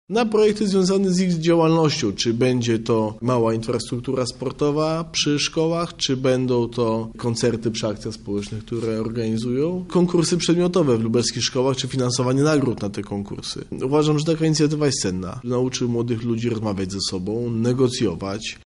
Pieniądze młodzi radni będą rozdysponowywać samorządnie i niezależnie – stwierdza Piotr Kowalczyk, przewodniczący Rady Miasta Lublin